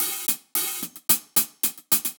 Index of /musicradar/ultimate-hihat-samples/110bpm
UHH_AcoustiHatA_110-03.wav